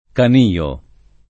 Canio [ kan & o ]